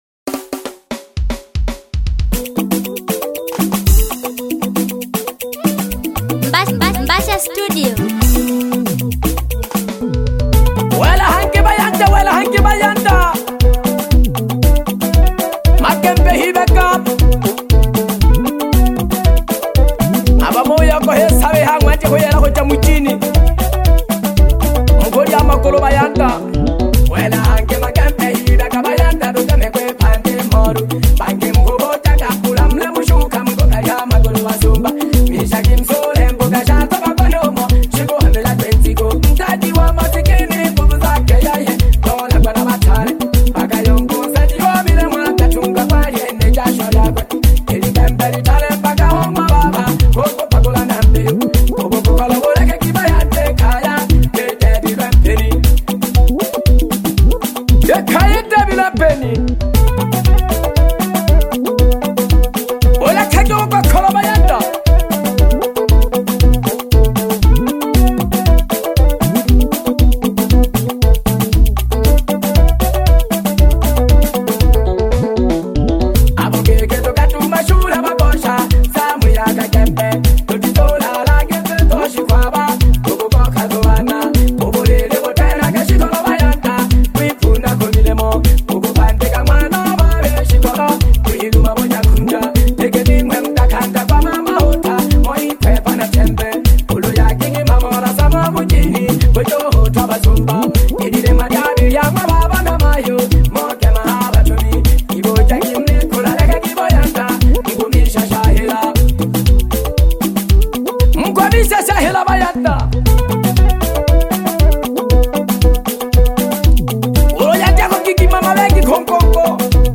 Asili music track
Asili song